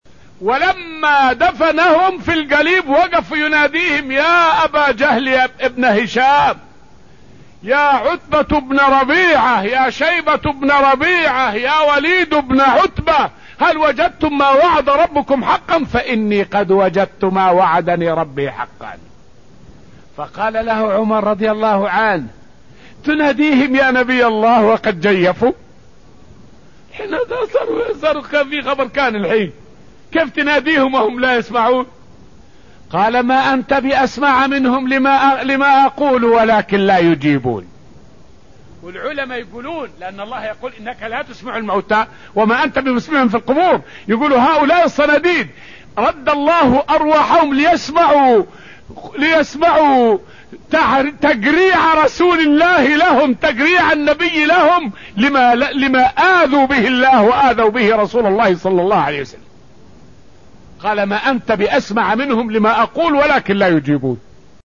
فائدة من الدرس الثاني من دروس تفسير سورة آل عمران والتي ألقيت في المسجد النبوي الشريف حول قول العلماء في سماع قتلى المشركين للنبي عليه السلام.